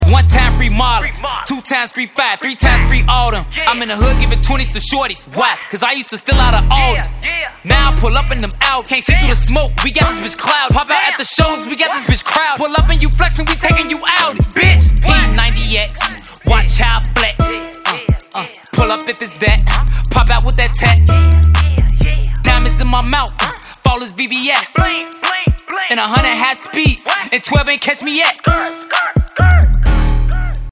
Rap - Hip Hop